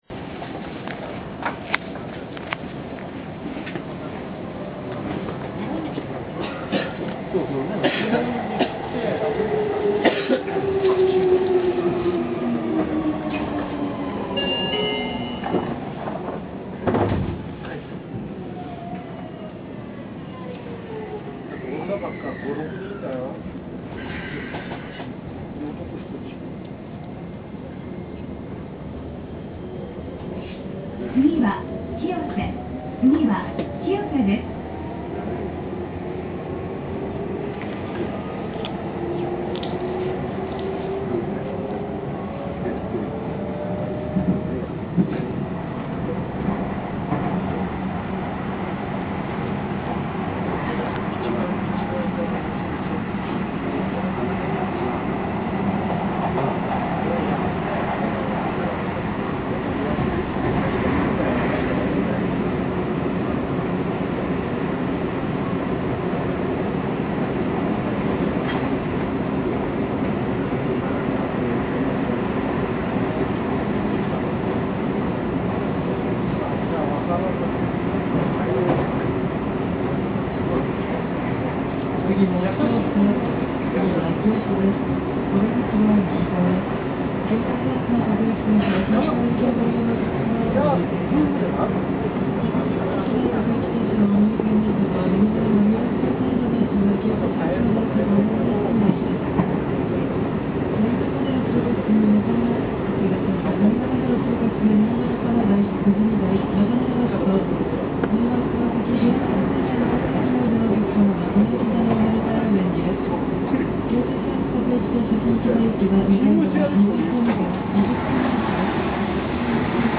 音声5　20000系の走行音